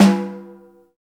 Index of /90_sSampleCDs/Roland - Rhythm Section/KIT_Drum Kits 7/KIT_Loose Kit
SNR SNAREL0P.wav